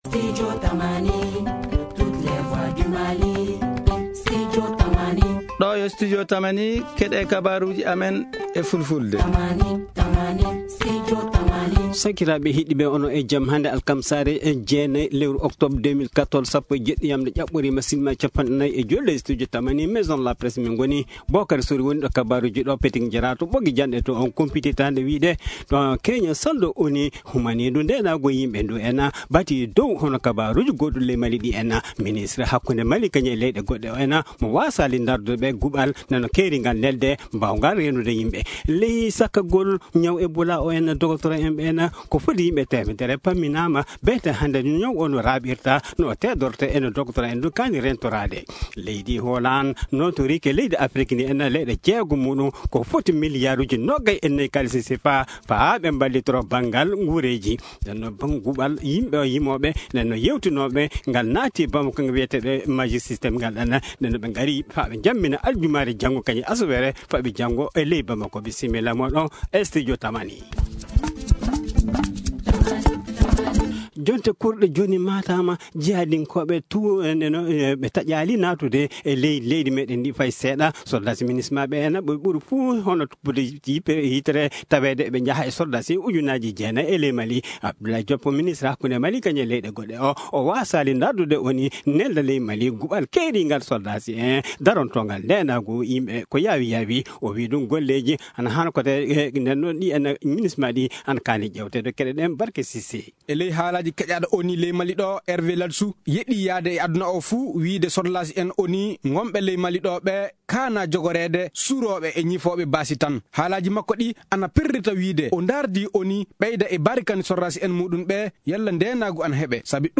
Journal en français: Télécharger